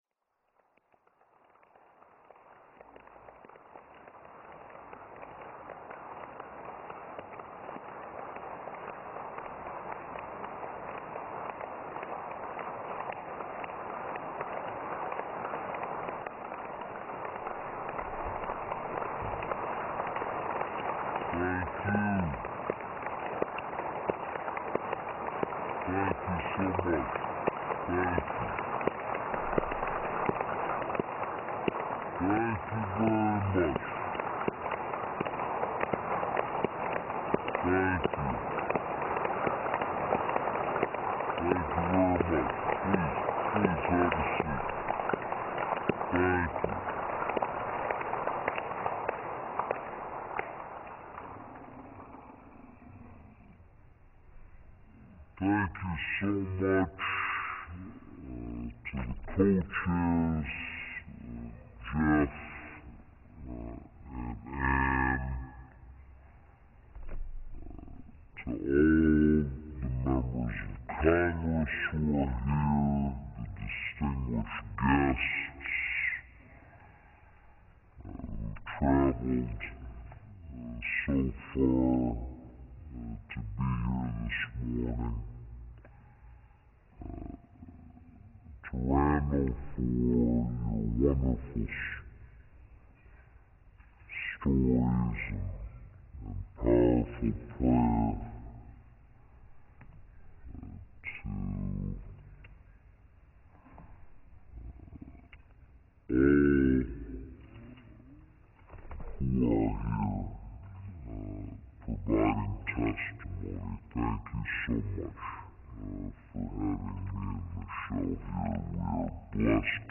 U.S. President Barack Obama speaks at the Fellowship Foundation National Prayer Breakfast